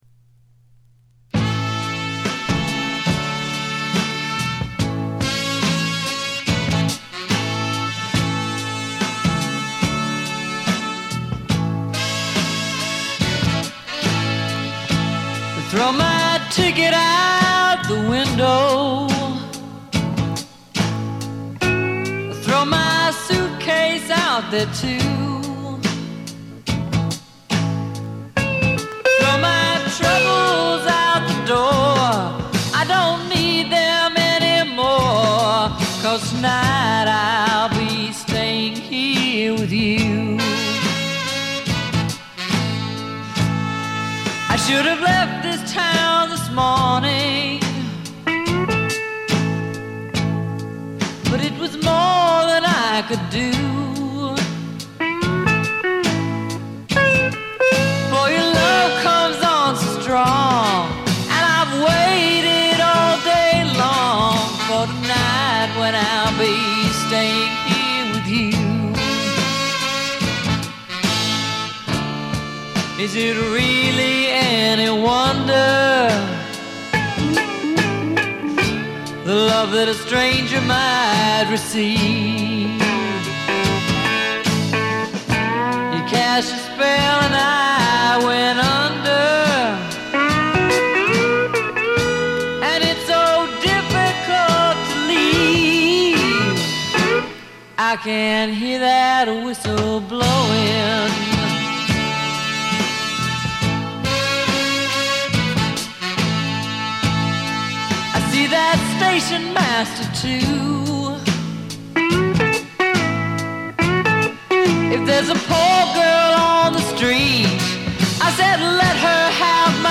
ほとんどノイズ感無し。
白ラベルのプロモ盤。モノ・プレス。
試聴曲は現品からの取り込み音源です。
Recorded At - Muscle Shoals Sound Studios